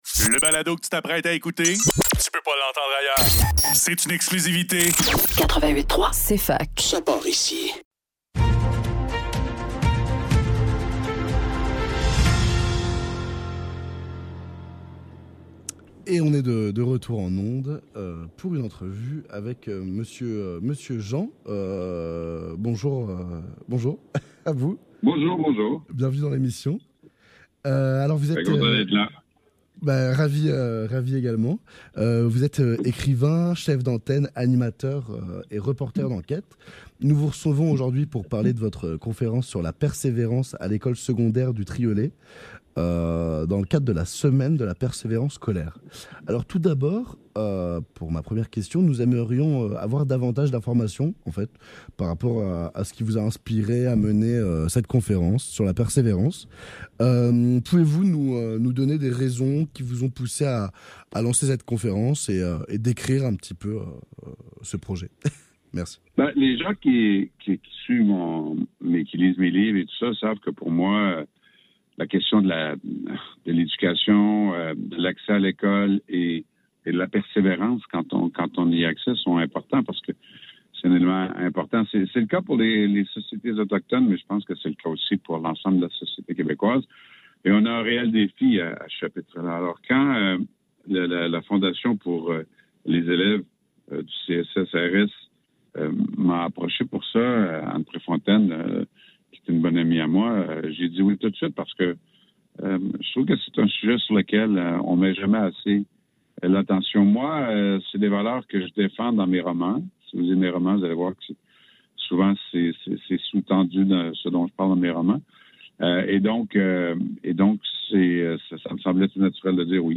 Le NEUF - Entrevue avec Michel Jean - 7 février 2024